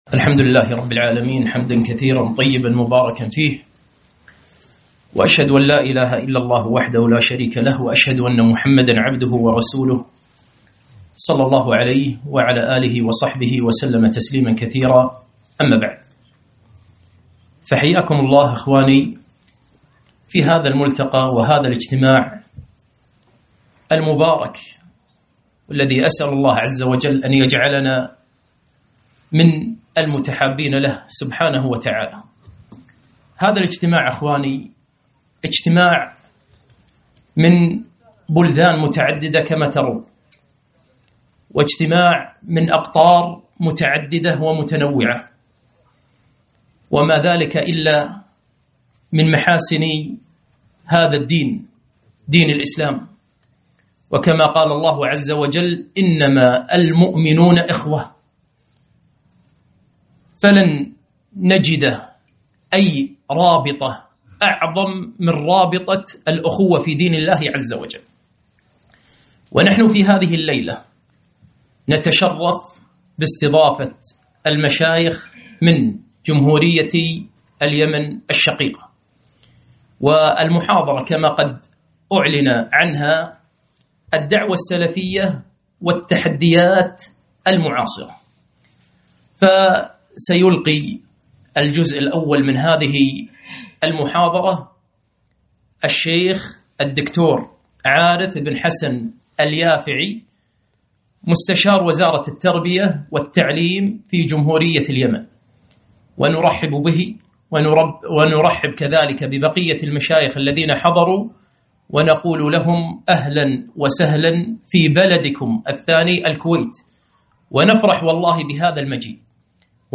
محاضرة - الدعوة السلفية والتحديات المعاصرة